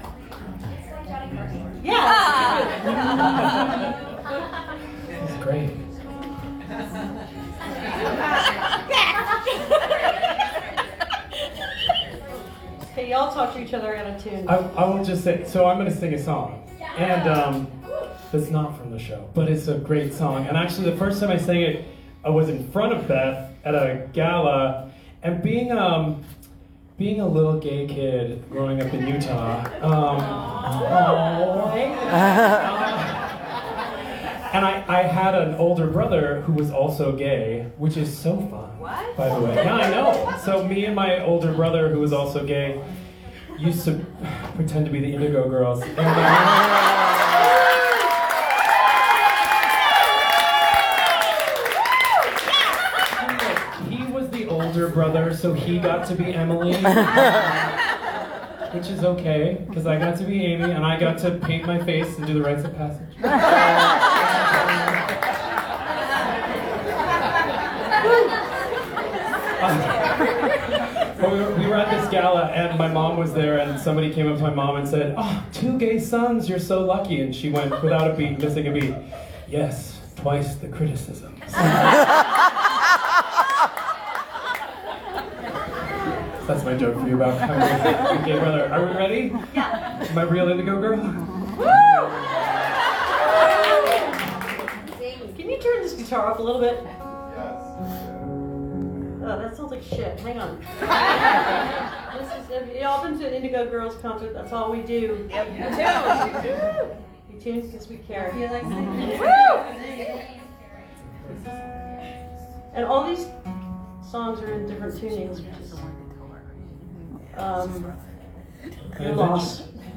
lifeblood: bootlegs: 2024-05-24: out of the box theatrics - new york, new york (emily saliers)
07. talking with the crowd (2:10)